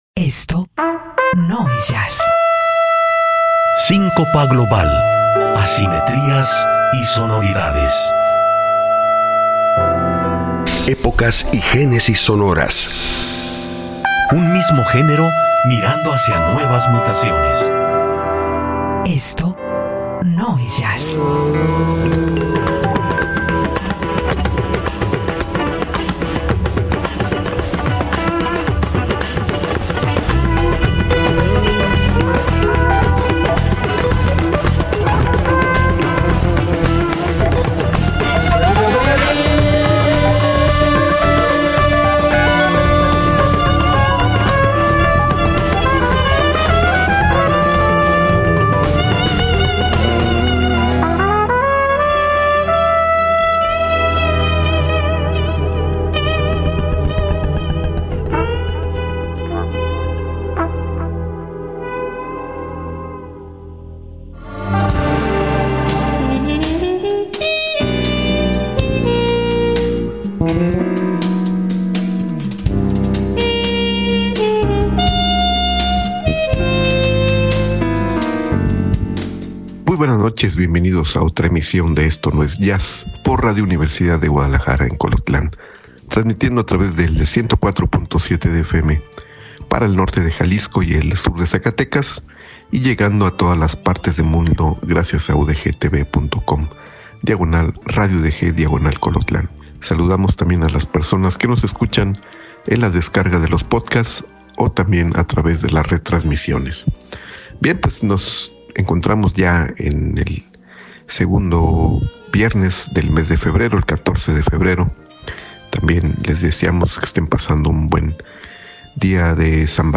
dueto alemán
el baterista brasileño